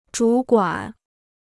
主管 (zhǔ guǎn): in charge; responsible for.